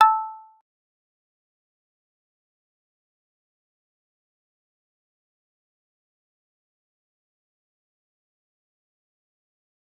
G_Kalimba-A5-pp.wav